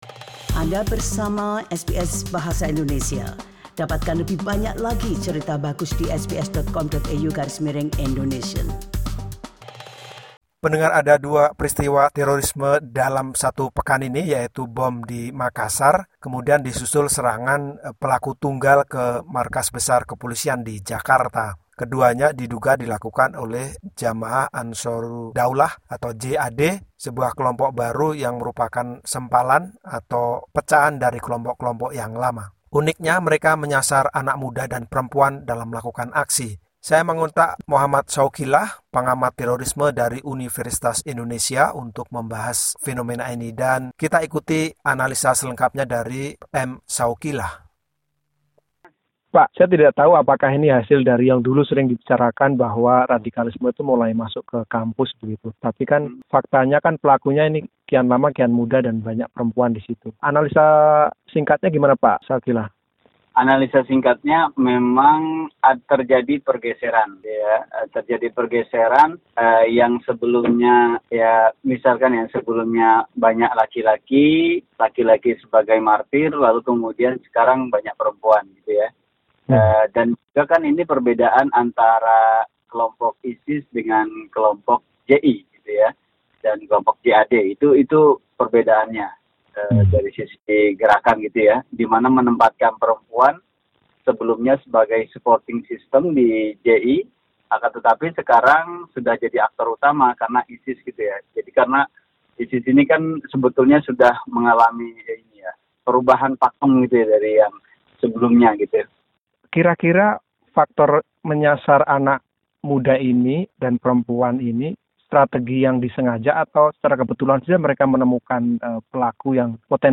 perbincangan